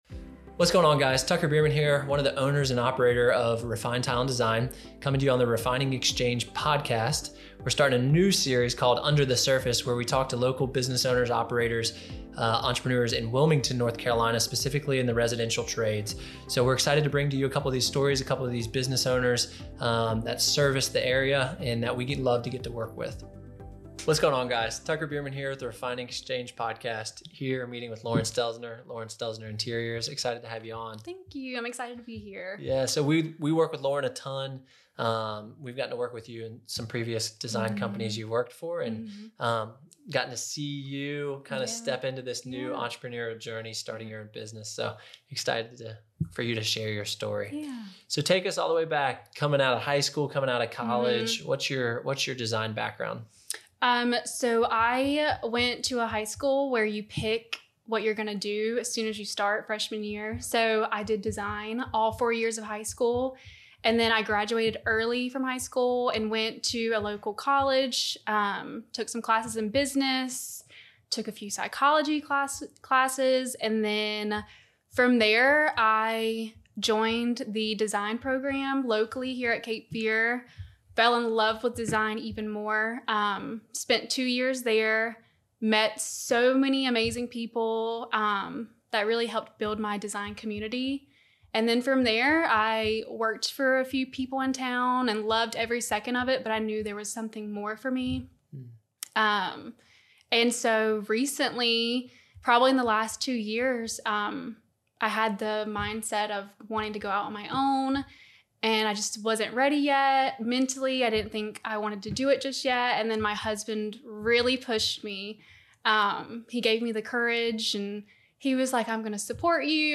This show dives into conversations with other small business owners and entrepreneurs exchanging tactical advice on implementing small changes (whether people or operations) and scaling more efficiently. Inevitably, at some point, every business faces sticking points that halt growth.